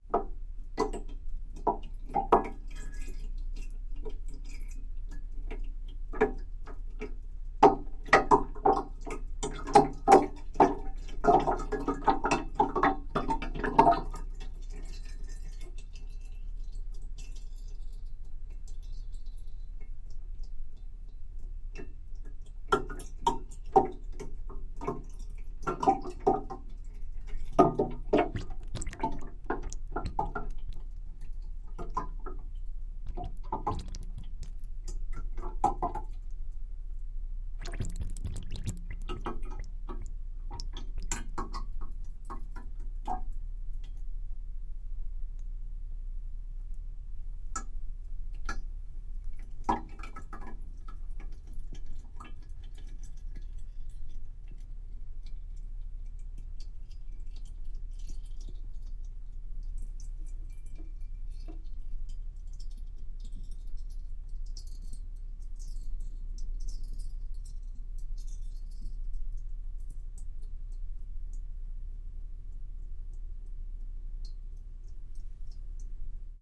Oil Splats | Sneak On The Lot
Liquid, Drips & Drops; Oil Poured From Tin, Dribbles Drops, Burbles And Drizzles ( Olive Oil Poured From Tin Into Glass Jar )